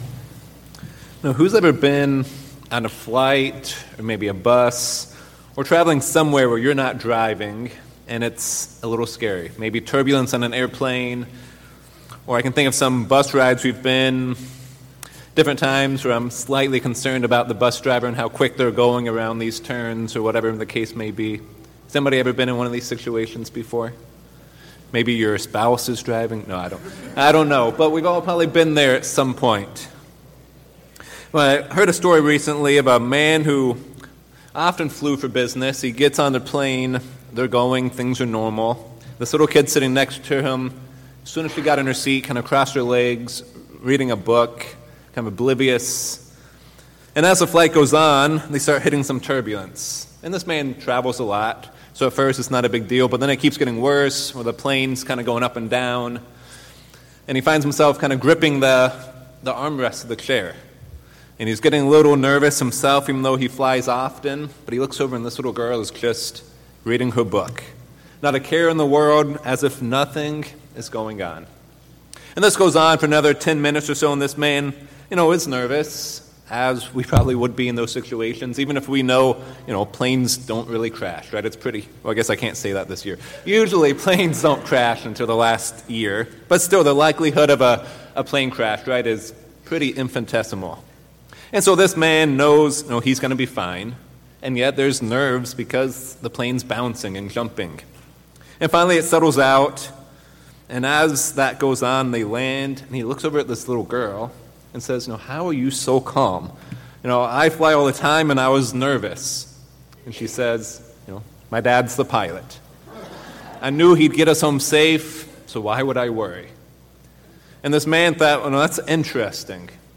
Sermons
Given in Columbus, OH Mansfield, OH